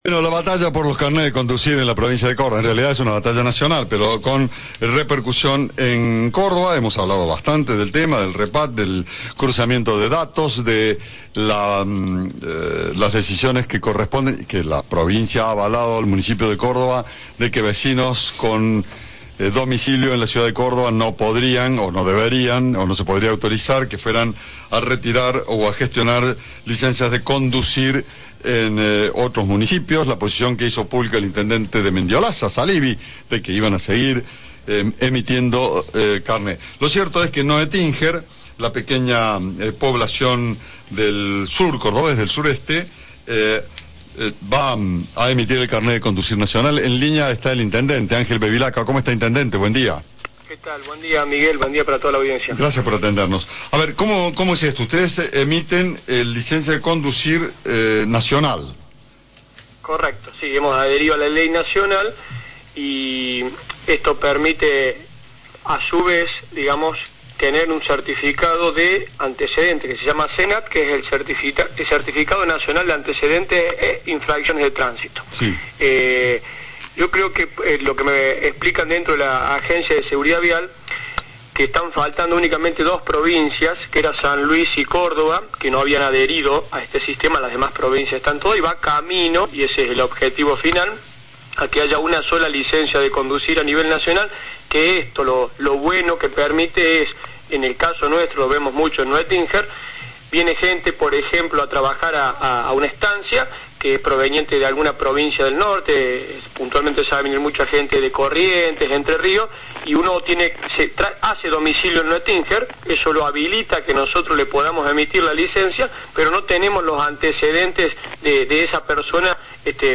El intendente Angel Bevilaqua explicó a Cadena 3 que la adhesión a la ley nacional, «permite tener un certifcado nacional de antecedentese infracciones de tránsito».